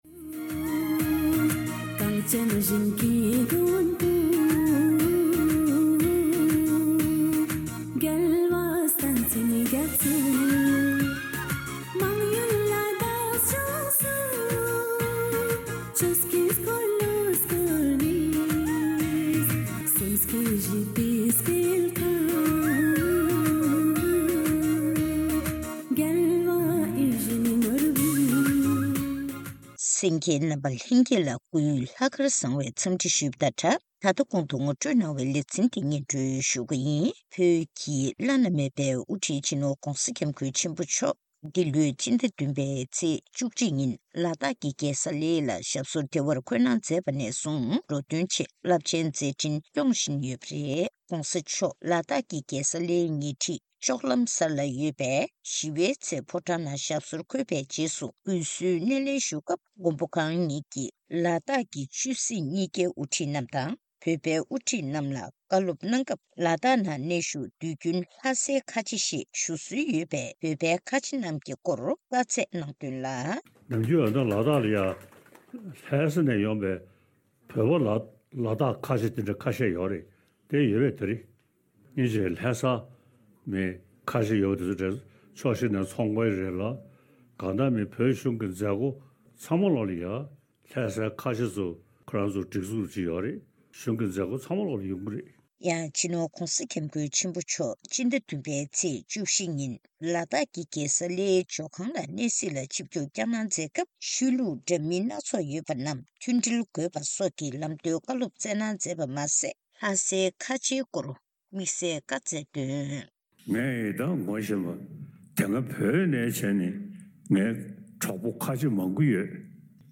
གནས་འདྲི་ཞུས་ནས་གནས་ཚུལ་ཕྱོགས་བསྒྲིགས་ཞུས་པ་ཞིག་གསན་རོགས་གནང།།